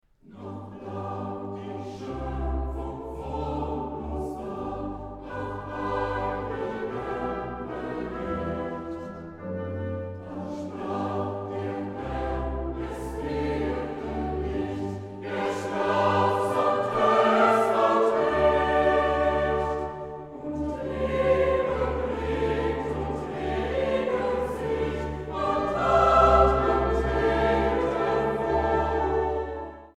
Feierstunde in Berlin-Lichtenberg am 6. November 2010